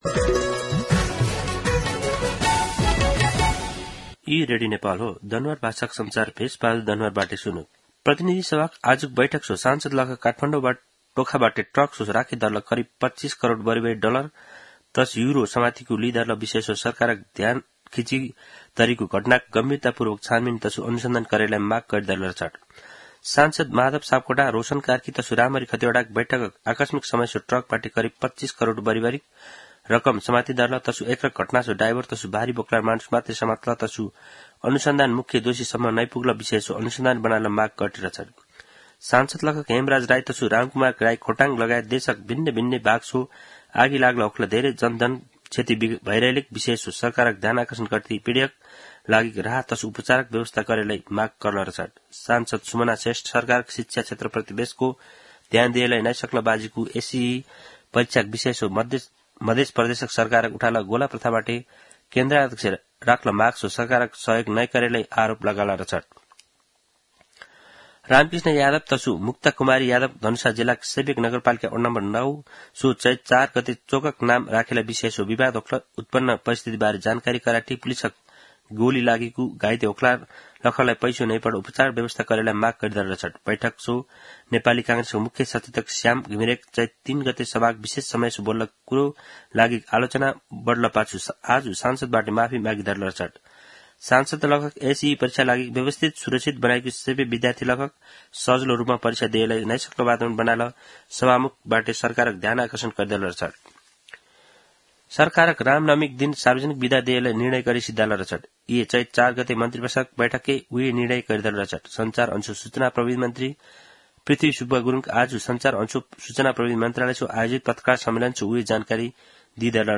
दनुवार भाषामा समाचार : ६ चैत , २०८१
Danuwar-News-12-06.mp3